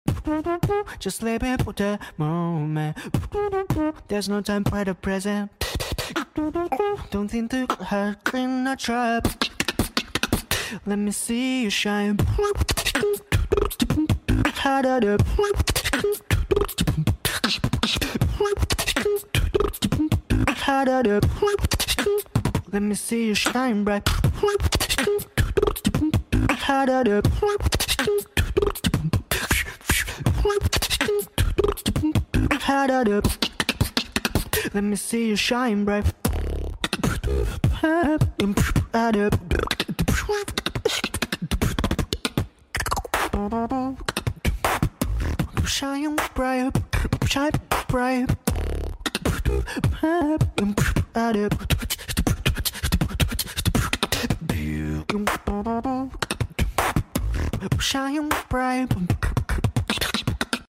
beatboxing